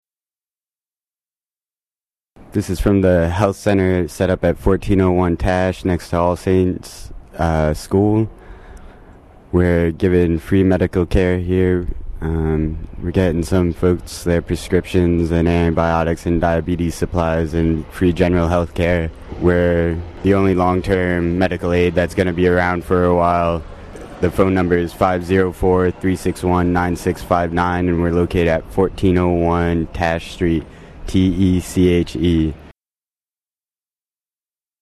Audio PSA:
wellness-psa_9-14-05.mp3